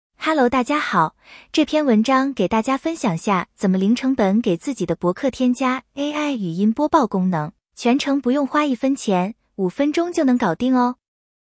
用到的工具：微软Edge-TTS
微软这个服务完全免费无调用次数限制，我用了快俩月了啥问题都没有，生成的语音也很自然，没有很多免费服务那种生硬的机械音。